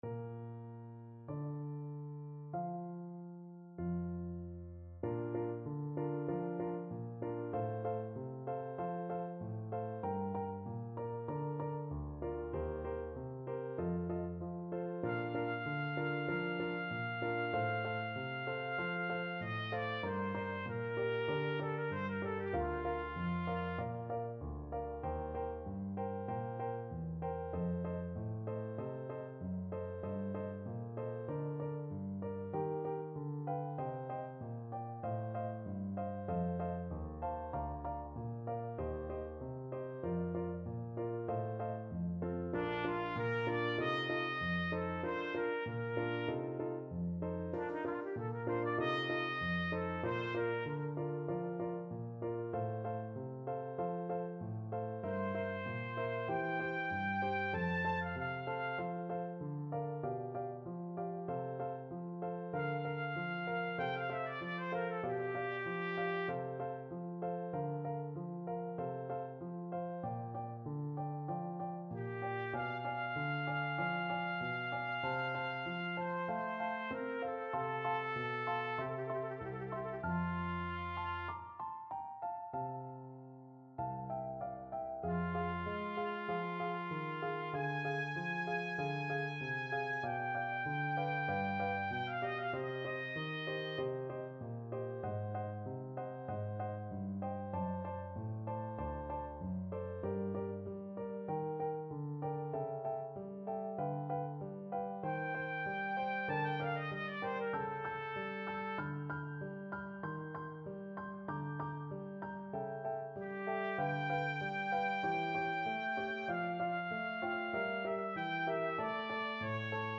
4/4 (View more 4/4 Music)
Adagio =48
Classical (View more Classical Trumpet Duet Music)
Relaxing Music for Trumpet